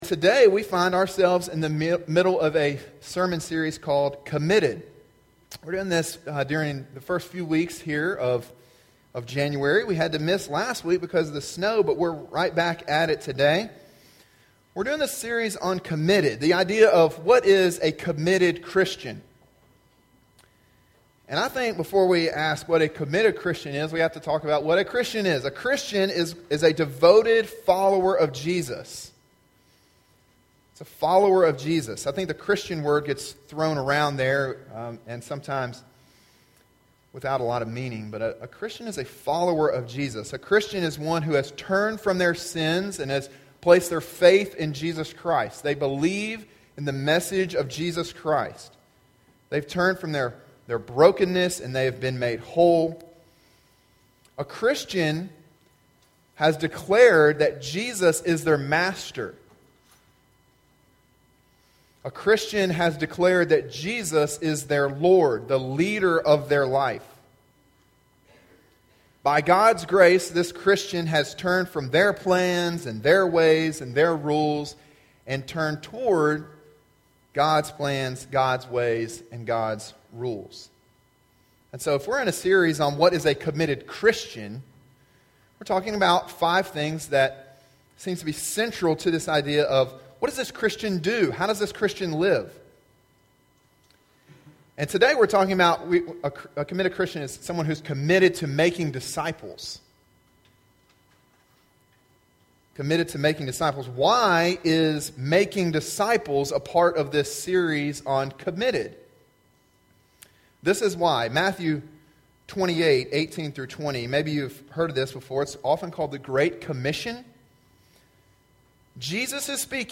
sermon1-21-18.mp3